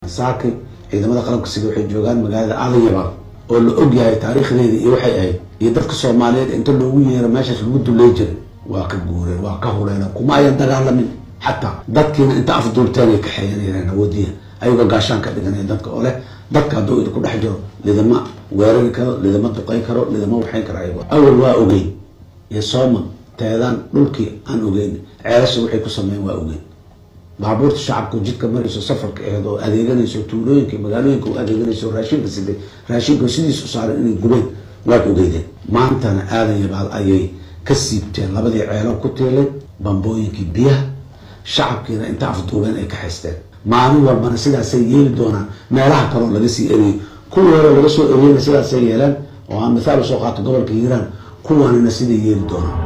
Waxaa sidatan maanta sheegay madaxweynaha dalka Soomaaliya Xasan Sheekh Maxamuud ka dib khubad u jeediyay